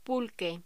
Locución: Pulque
voz
locución
Sonidos: Voz humana